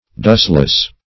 \dust"less\ (d[u^]st"l[e^]s)